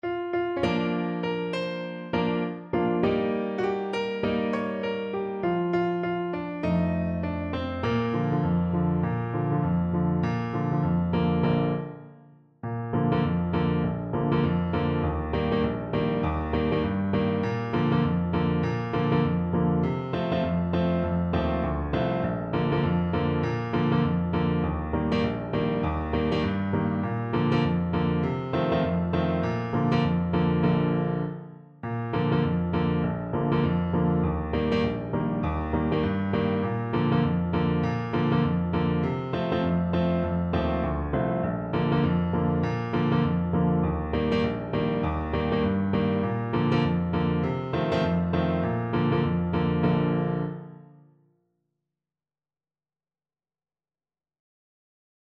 4/4 (View more 4/4 Music)
Bb major (Sounding Pitch) (View more Bb major Music for Trombone )
Swing 16, =100